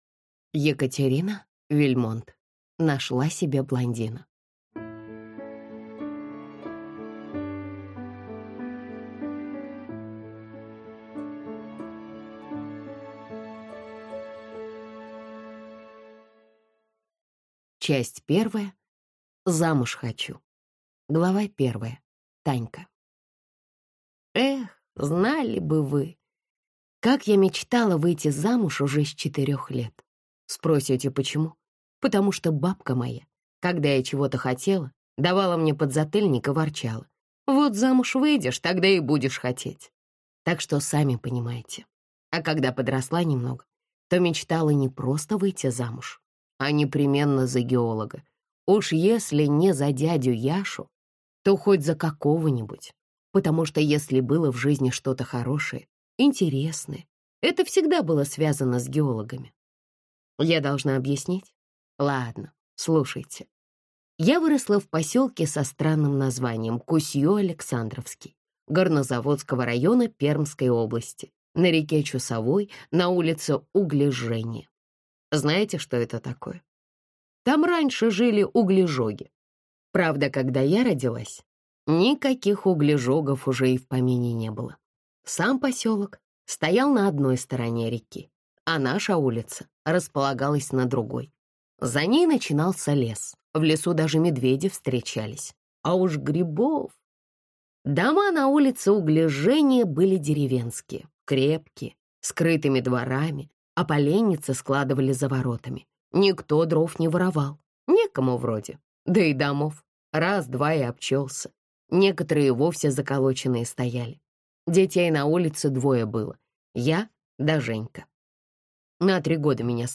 Аудиокнига Нашла себе блондина!